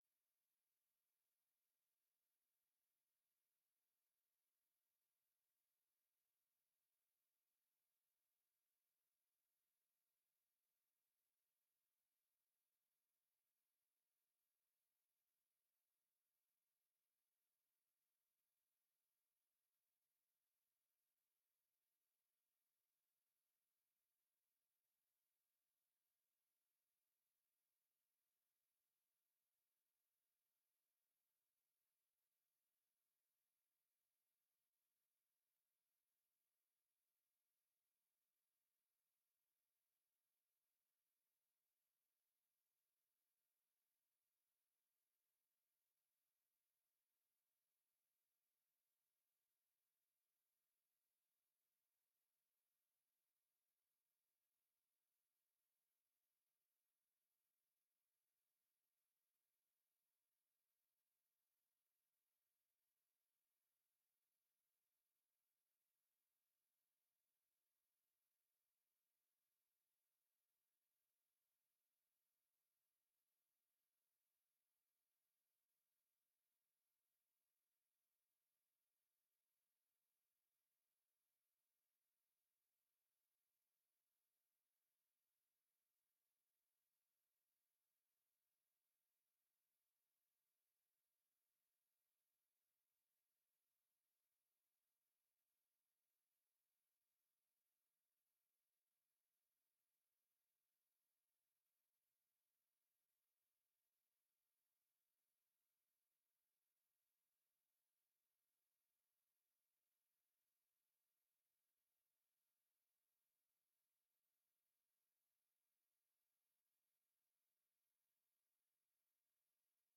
Download de volledige audio van deze vergadering
Locatie: Voorrondezaal Lingewaal